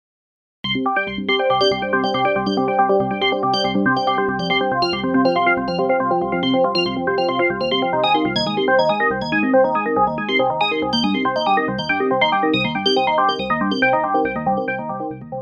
Select an organ sound with delay Fx on your synthesizer, and play some chords. As you can hear in the MP3, the arpeggio is really "organic"! Depending on the number of notes which are played, and the order of changes, the resulting sequence is sometimes inpredictable, but still "rhythmic" and "melodic".